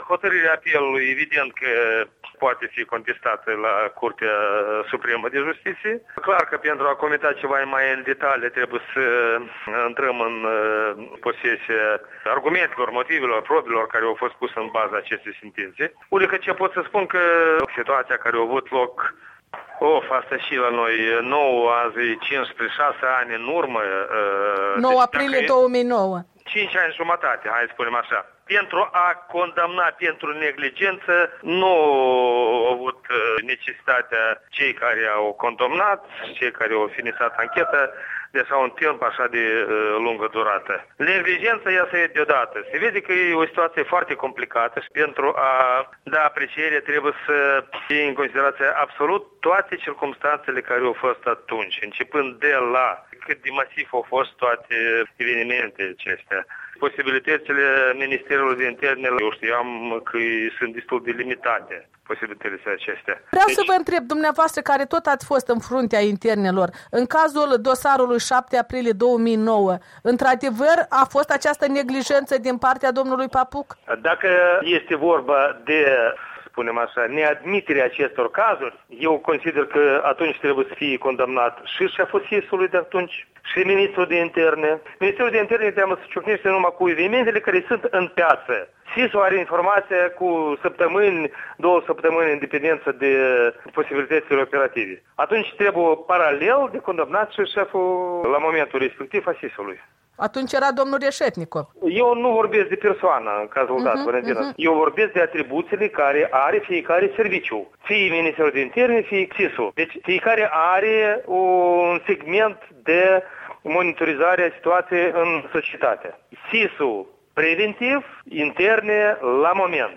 Interviu cu Vladimir Ţurcan